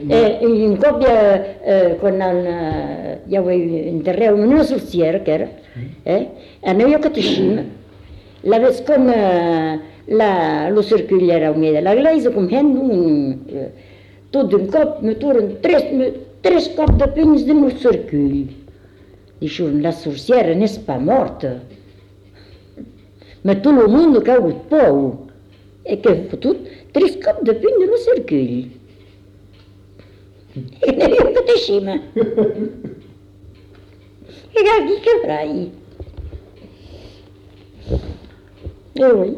Aire culturelle : Bazadais
Lieu : Cazalis
Genre : conte-légende-récit
Effectif : 1
Type de voix : voix de femme
Production du son : parlé